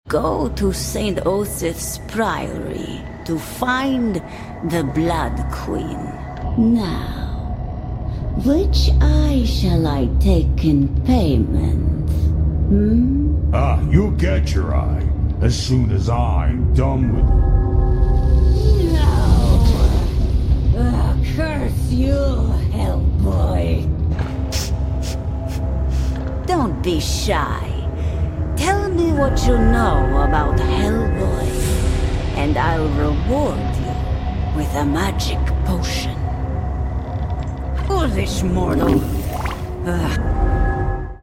GAME_TheSandbox-Hellboy2019_BabaYaga-F-EU-Eld-Witch.mp3